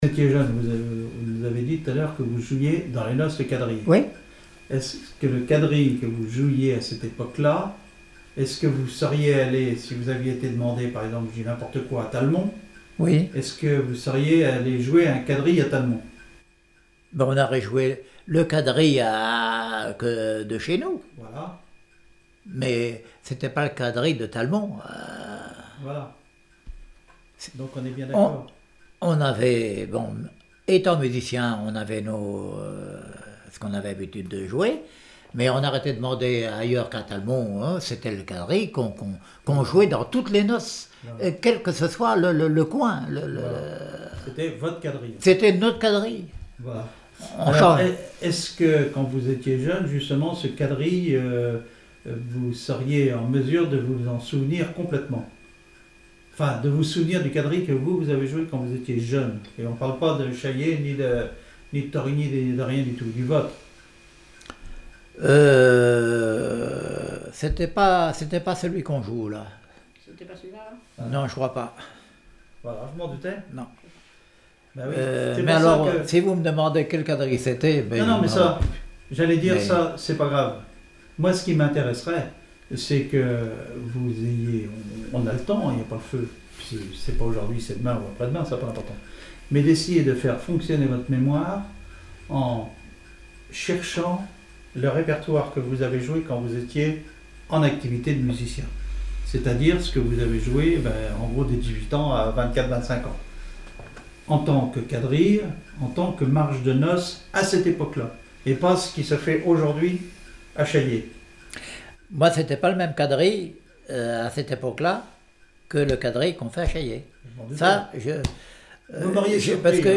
Témoignage comme joueur de clarinette
Catégorie Témoignage